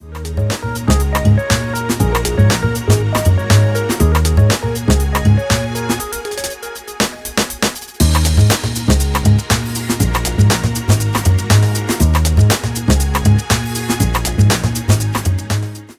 In this example I dissected the main drum loop into its component parts and created a new pattern by placing the slices in various locations. I also created some buffer repeats and used pan and volume automation to mix things up a little.
The programmed fill in the mix.